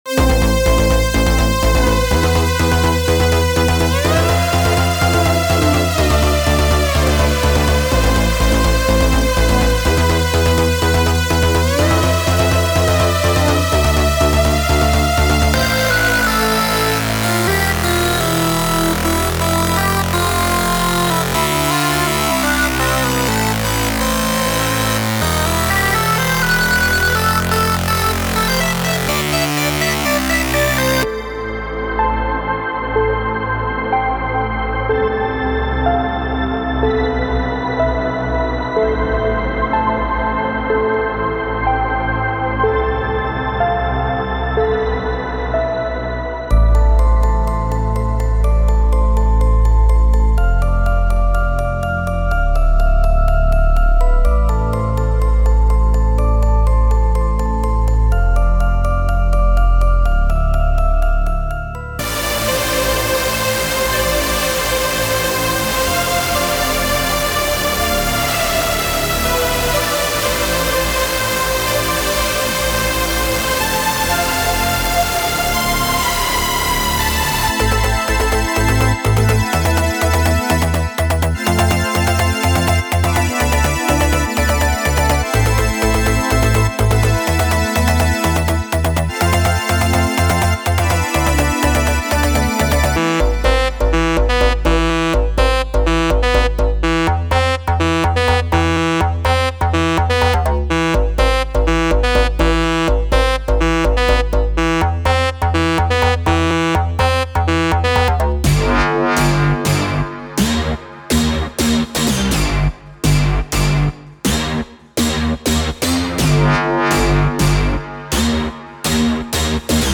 90s Classic Ambient Disco / Nu Disco Dubstep Electro House Future House House Multi-genre Pop Progressive House Synthwave / Retrowave Trance Trap Uplifting Trance
220 EDM Presets For Spire
From earth-shaking basses to soaring leads, this pack is packed with inspiration for producers of Electro House, Hard Electro, Complextro, Dubstep, Trap, Trance, and beyond.
• Includes a wide variety of basses, leads, plucks, pads, arps, and FX sounds
• Optimized for maximum clarity, punch, and power in your mix